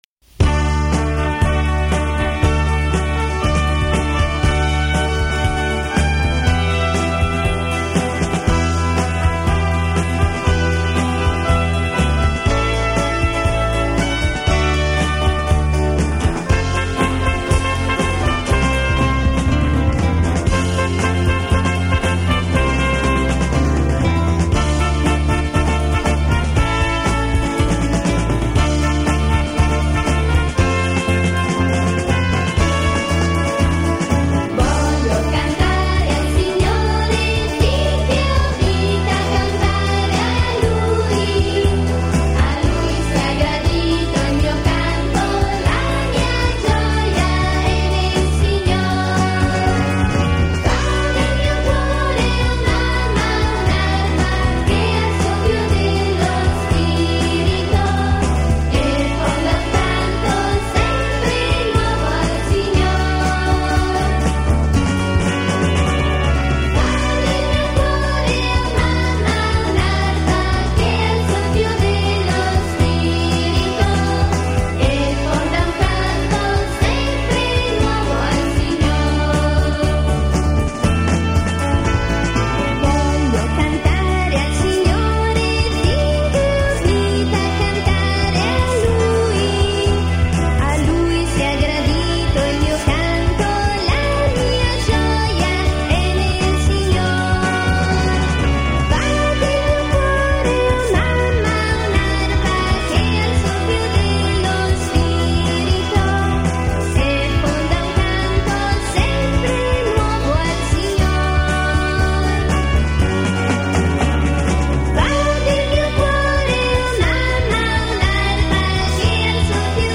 Canto per la Decina di Rosario e Parola di Dio: Voglio cantare al Signore